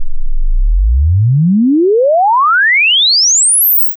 oscillator-sine-expected.wav